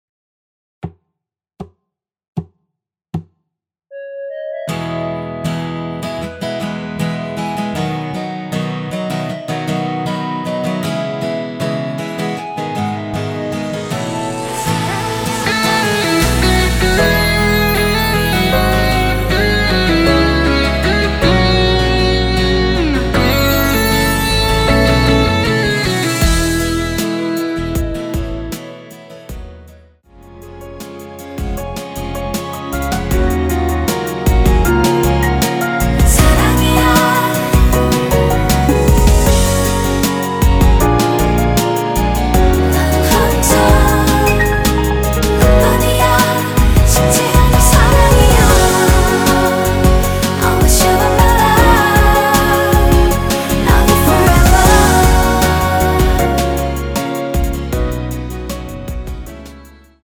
전주 없이 시작하는 곡이라서 노래하기 편하게 카운트 4박 넣었습니다.(미리듣기 확인)
원키 멜로디와 코러스 포함된 MR입니다.(미리듣기 확인)
앞부분30초, 뒷부분30초씩 편집해서 올려 드리고 있습니다.
중간에 음이 끈어지고 다시 나오는 이유는